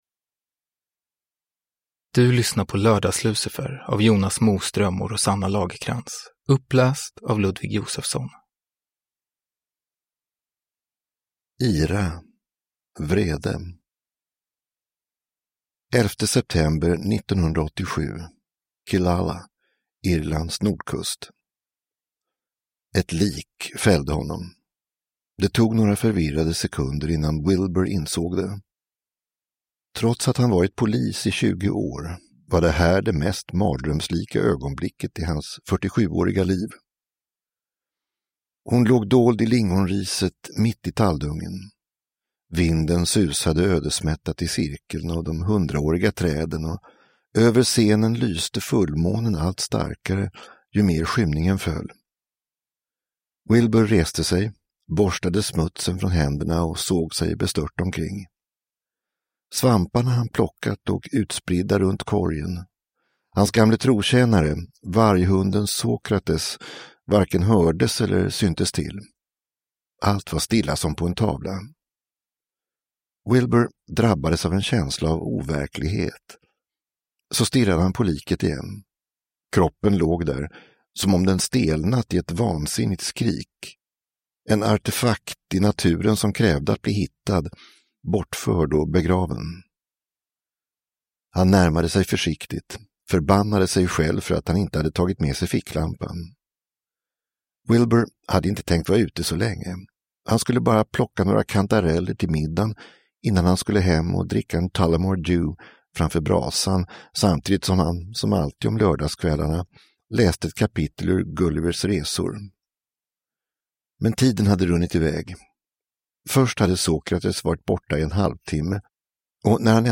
Lördags-Lucifer – Ljudbok